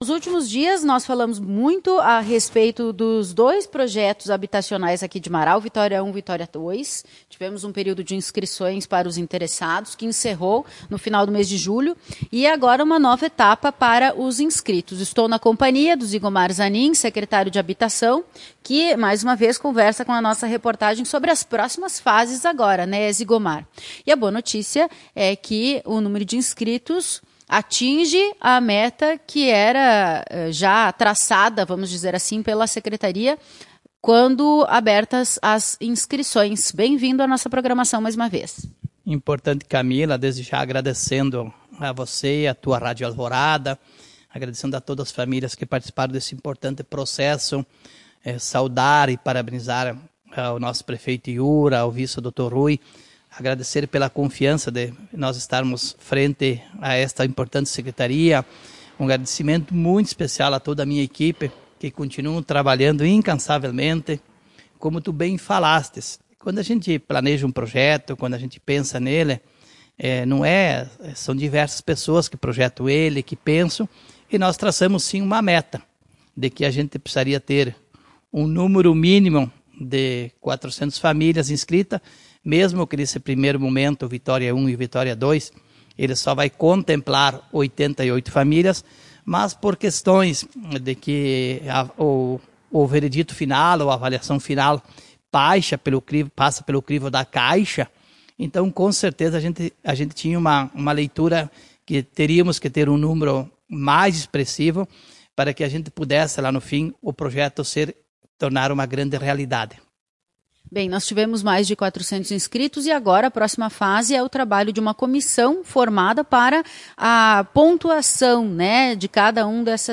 Em entrevista para a emissora, o secretário revelou que no ano que vem deve ser lançado o Vitória 3.